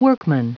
Prononciation du mot workman en anglais (fichier audio)
Prononciation du mot : workman
workman.wav